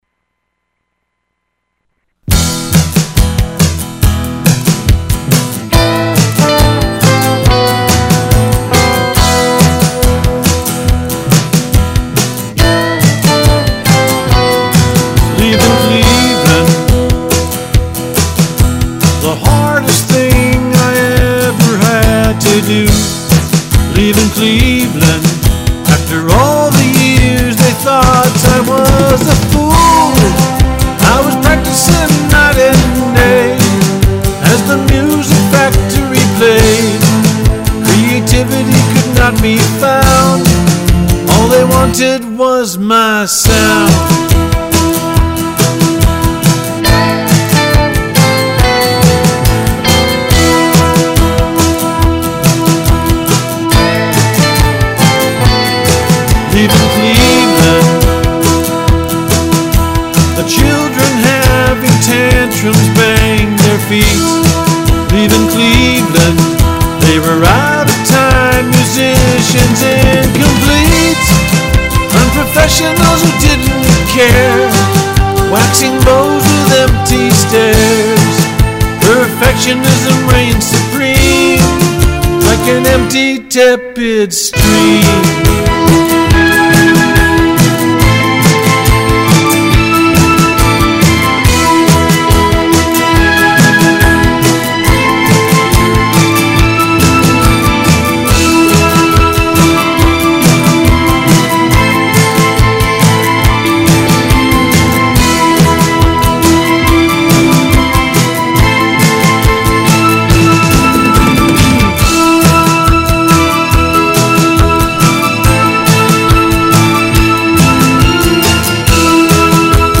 guitar solos